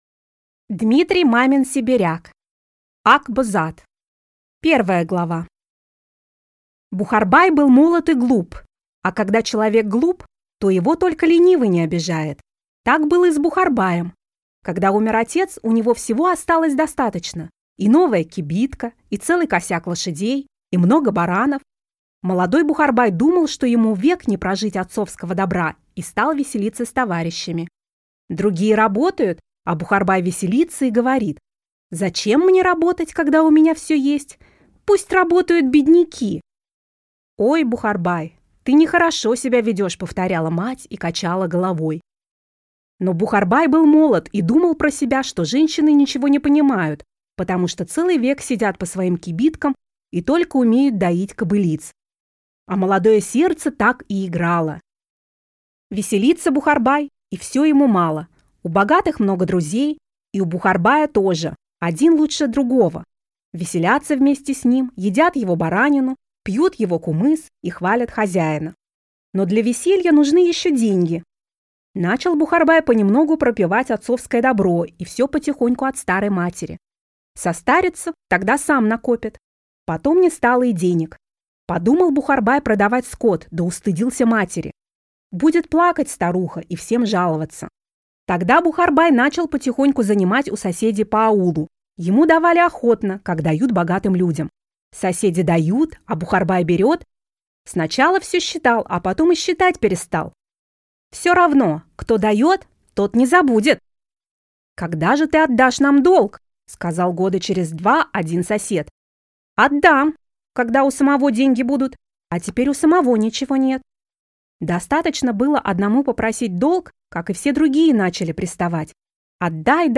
Аудиокнига Ак-Бозат | Библиотека аудиокниг
Прослушать и бесплатно скачать фрагмент аудиокниги